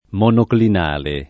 [ m q noklin # le ]